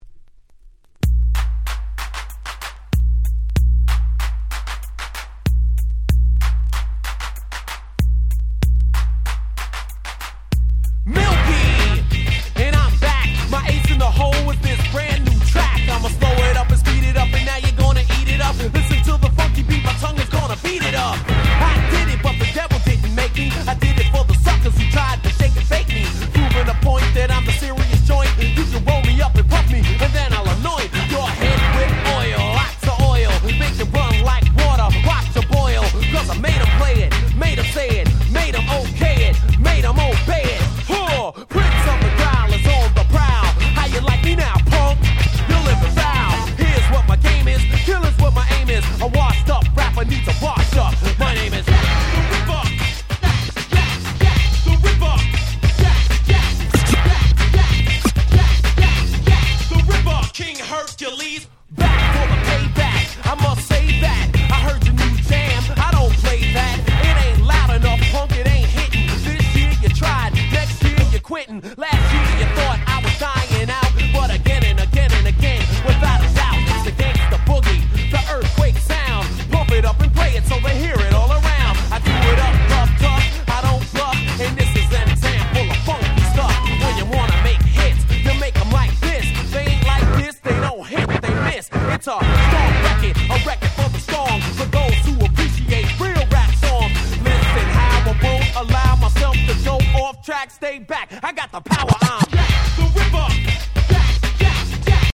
88' Smash Hit Hip Hop !!
問答無用のOld School Hip Hop Classics !!